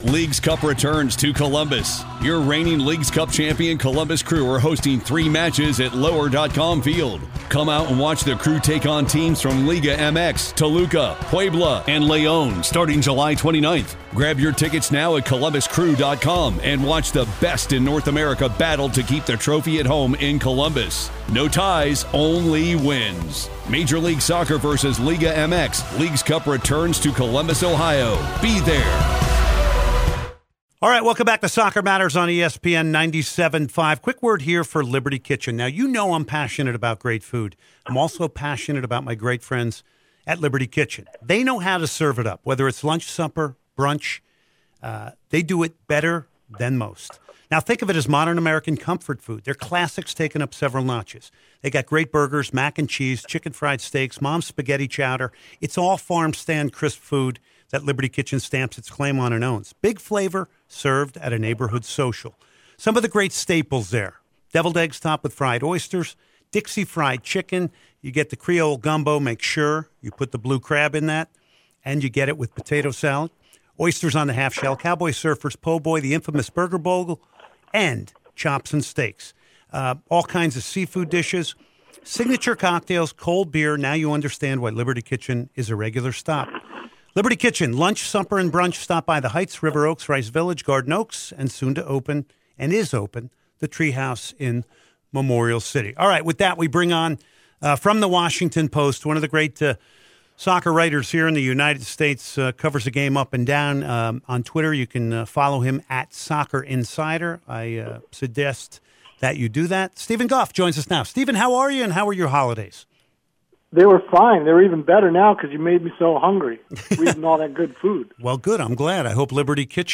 Bonus Interview